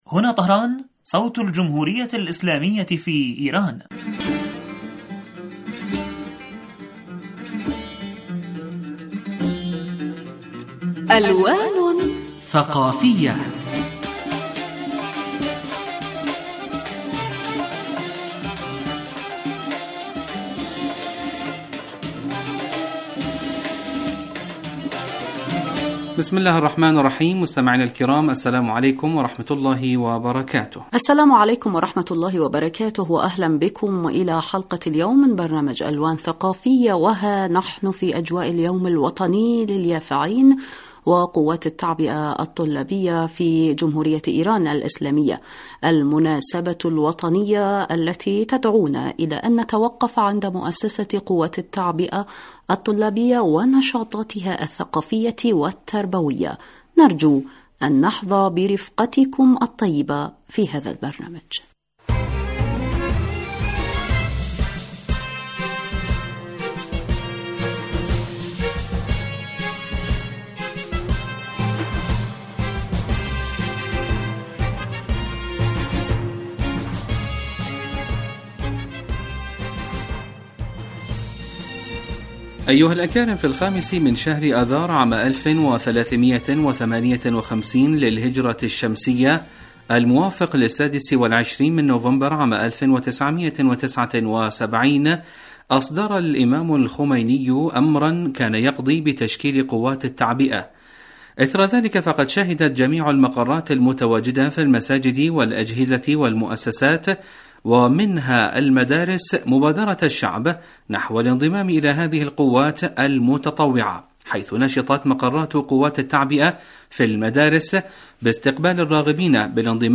لقاء ثقافي وأدبي یجمعنا بکم أسبوعیا عبر تجوال ممتع في أروقة الحقول الثقافیة والحضاریة والأدبیة لإيران الإسلامية ویشارکنا فیه عدد من الخبراء وذووي الاختصاص في الشأن الثقافي الإيراني لإيضاح معالم الفن والأدب والثقافة والحضارة في إیران